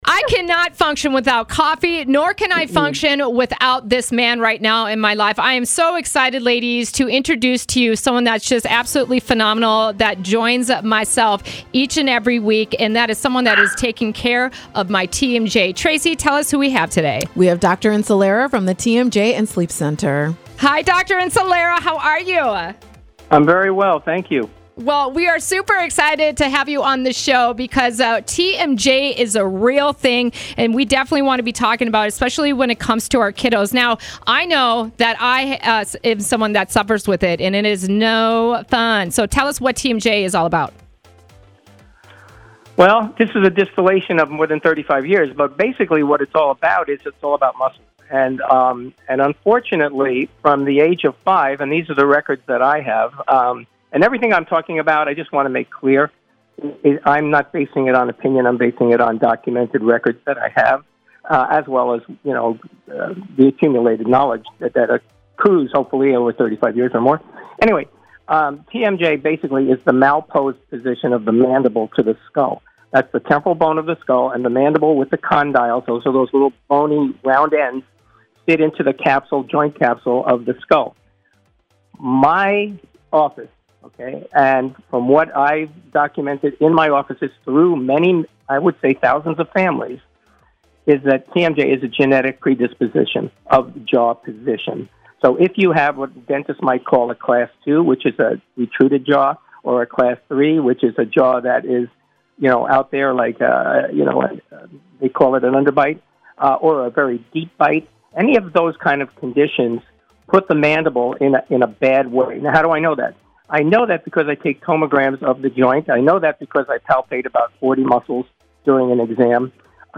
Listen to this interview to hear more about TMJ. The progression of leaving this untreated over time can cause long-term problems such as pain in your joints, neck pain, and back pain.